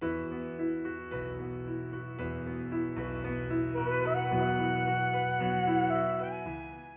El fitxer s176.wav és un fitxer WAV amb qualitat CD: 44.100 Hz, 16 bit i estèreo.